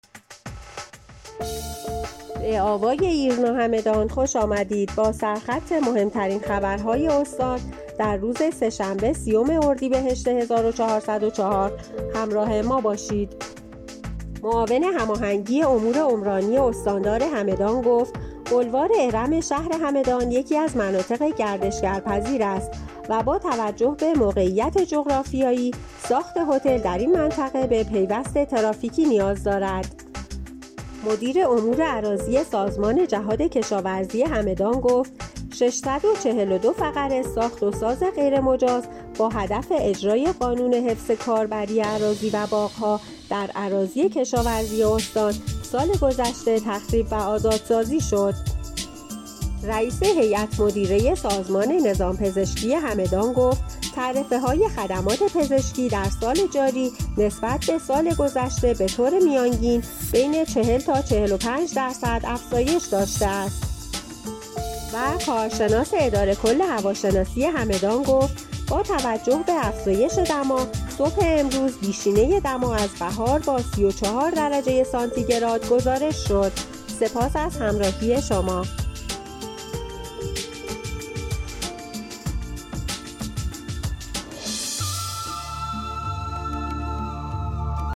همدان- ایرنا- مهم‌ترین عناوین خبری دیار هگمتانه را هر شب از بسته خبر صوتی آوای ایرنا همدان دنبال کنید.